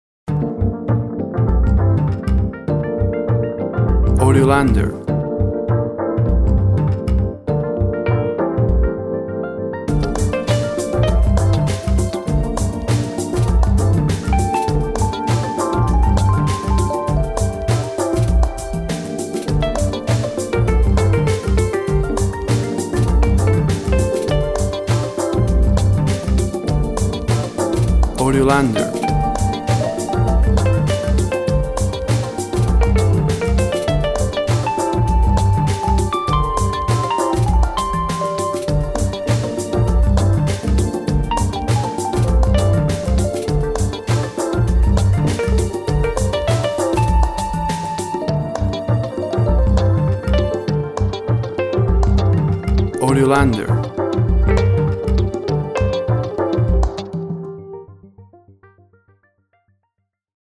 Urban Music, acid jazz for night scenery.
Tempo (BPM) 100